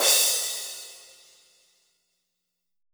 METALCRSH.wav